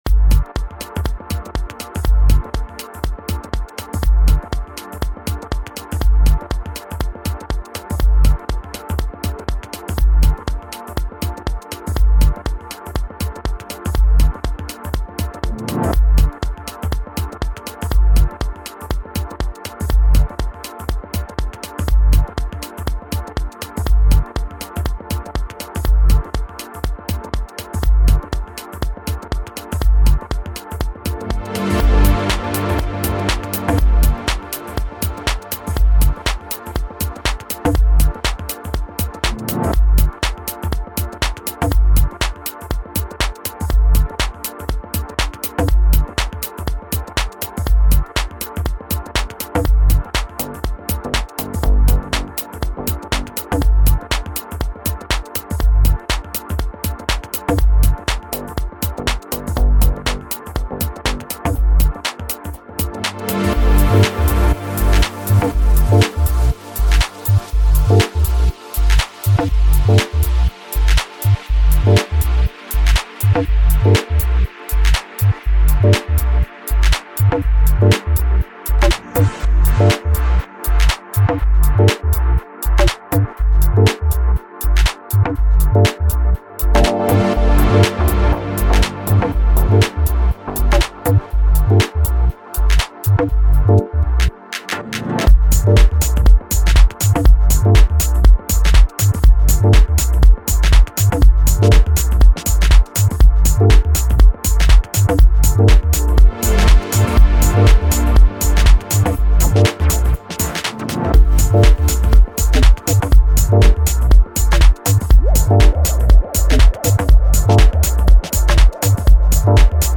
это динамичная трек в жанре EDM